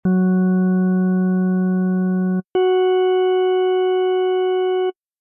We are starting to get into the really high frequencies now, and the overtones we are adding at this stage will add some real brightness to the tone.